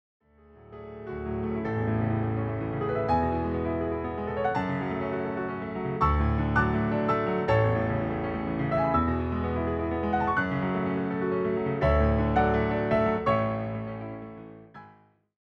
translate to a solo piano setting.